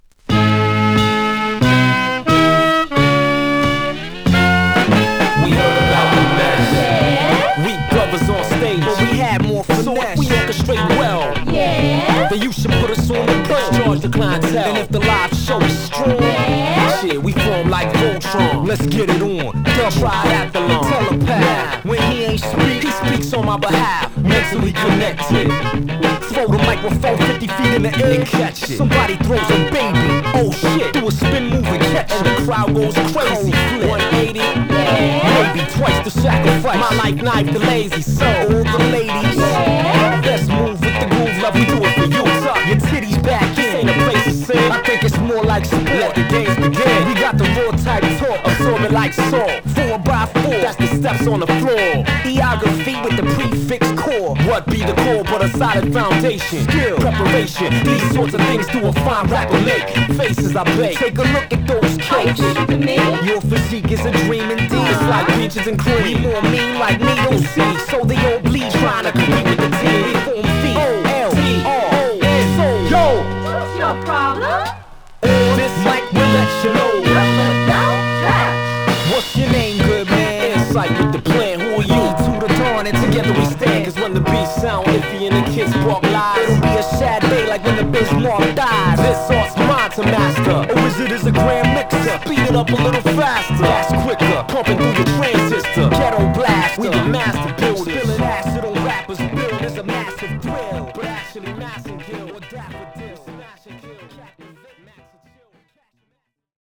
類別 饒舌、嘻哈